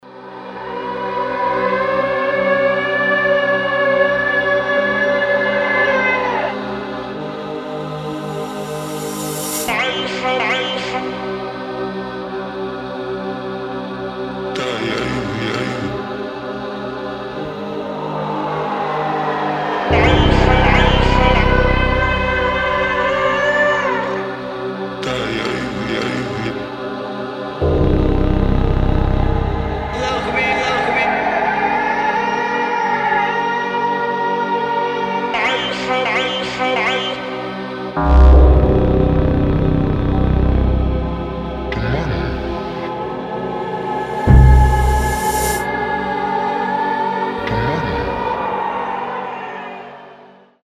инструментальные
печальные